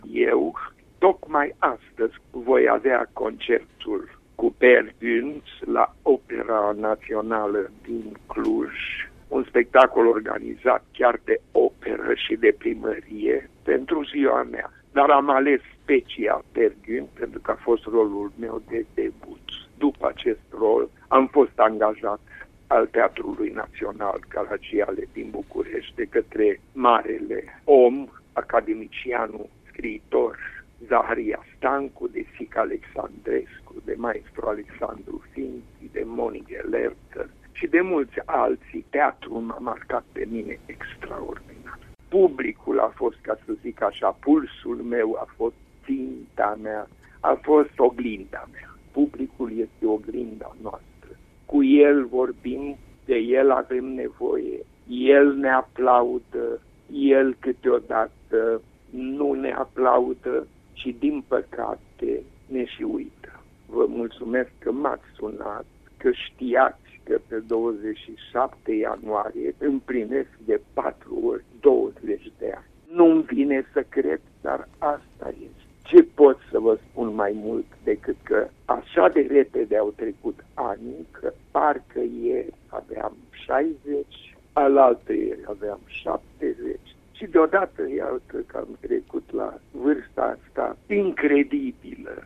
Florin Piersic: